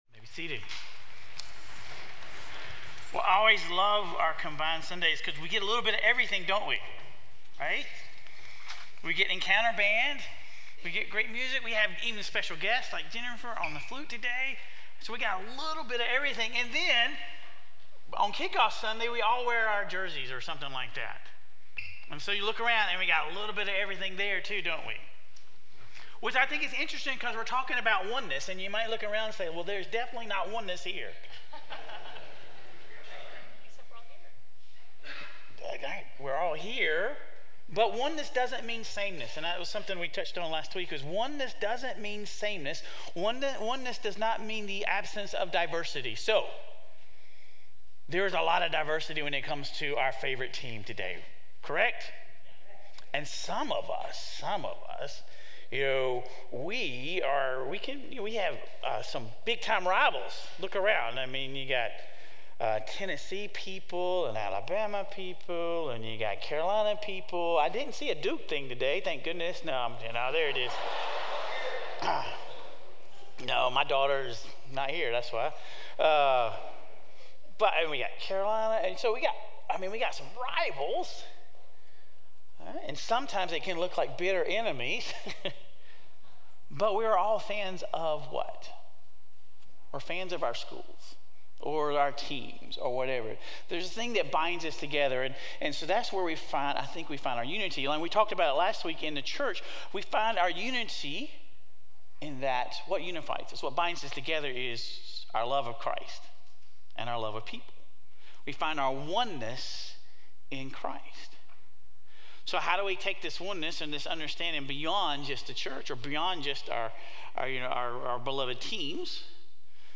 How do we keep our unity focused on our identity as followers of Christ, while extending love, fellowship, and community to our neighbors even when we disagree? Sermon Reflections: What does oneness in Christ mean to you, and how can it coexist with diversity in the church and broader society?